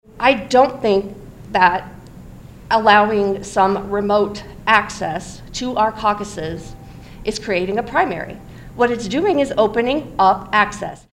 Nielsen says Republicans are inserting themselves into Democratic Party business.